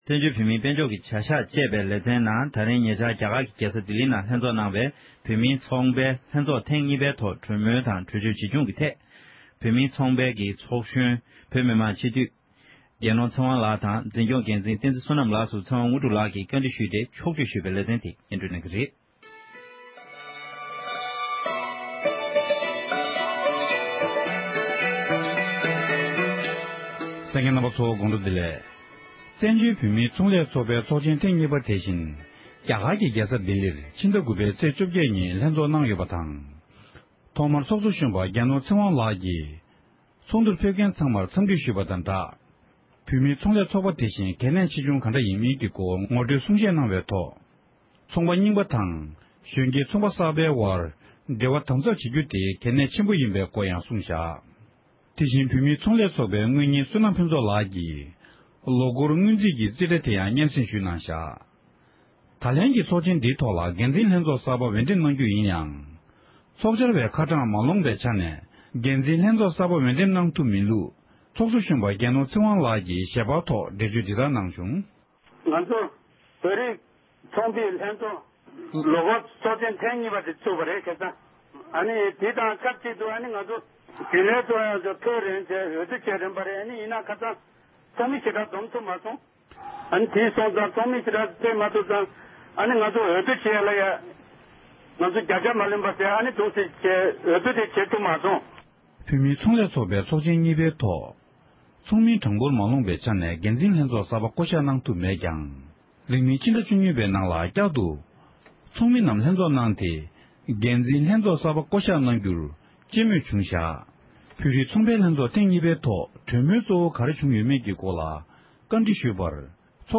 བོད་མིའི་ཚོང་ལས་བདེ་ཚོགས་ཀྱི་ཚོགས་ཆེན་ཐེངས་གཉིས་པའི་ཐོག་གྲོས་ཆོད་ཇི་བཞག་གི་ཐོག་བཅའ་འདྲི་ཞུས་པ།